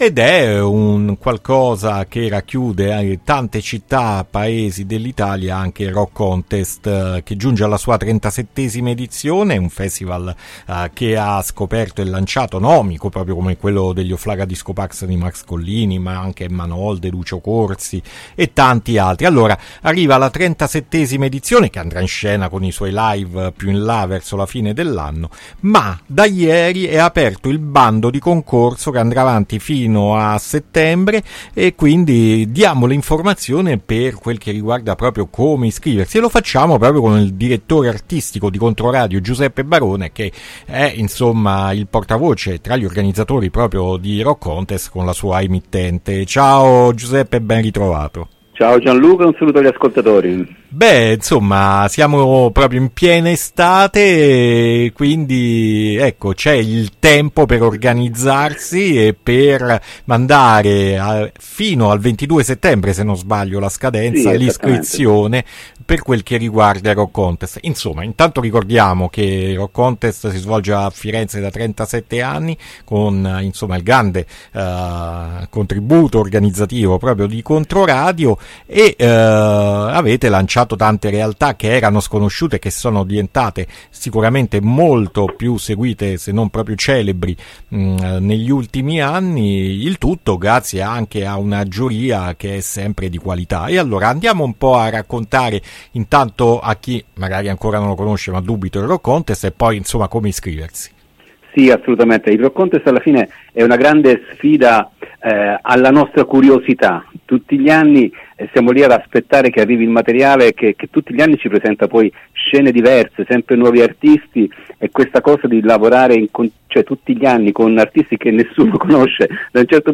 INTERVISTA BANDO CONCORSO ROCK CONTEST AD ALTERNITALIA 1-8-2025